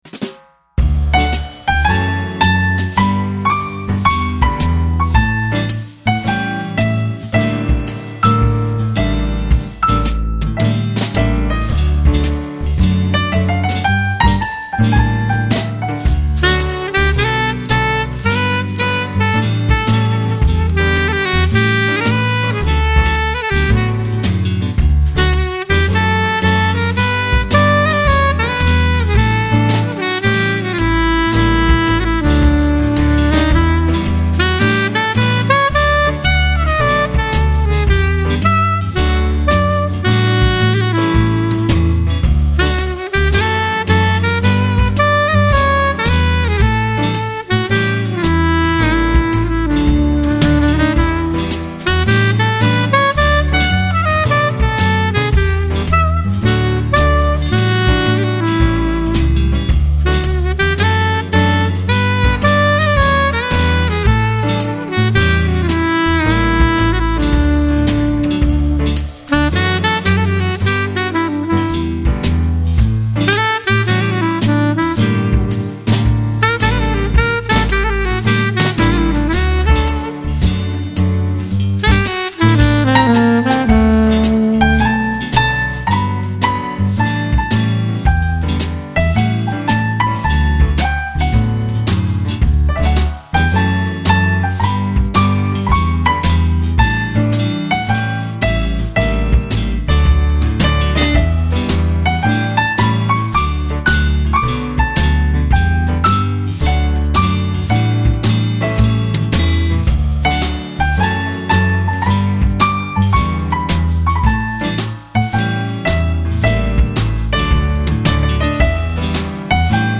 (Instrumental Jazz)
all done in a cool instrumental jazz style.